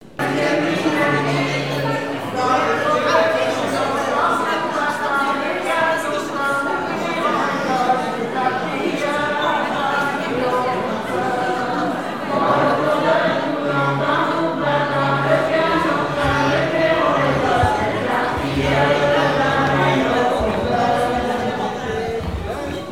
Fonction d'après l'informateur gestuel : à marcher
Genre énumérative
Catégorie Pièce musicale inédite